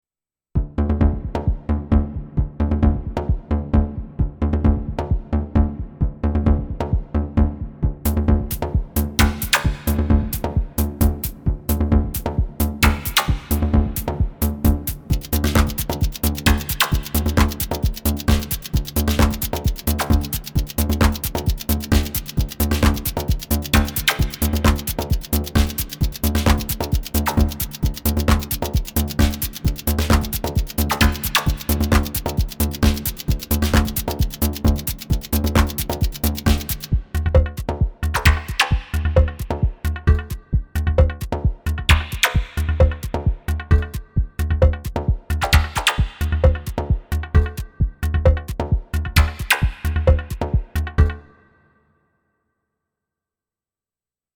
First one, a simple arpeggio with some sparse beats.